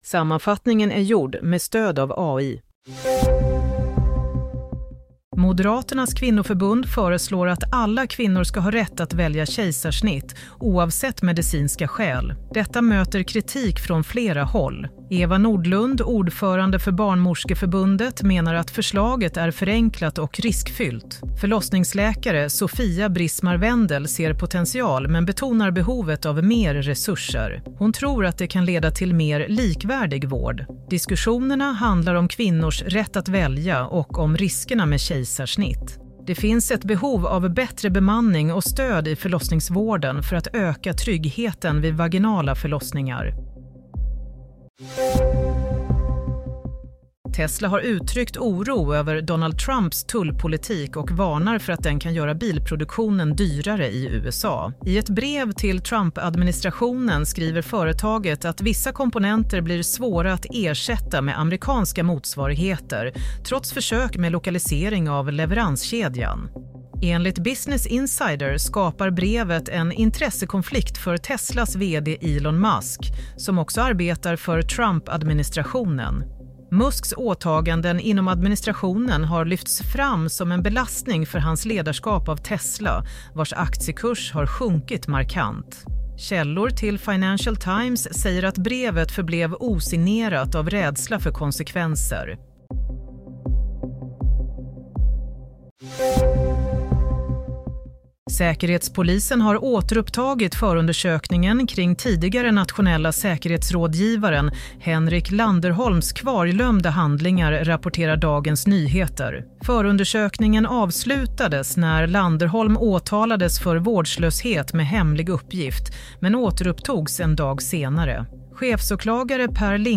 Nyhetssammanfattning - 14 mars 16:00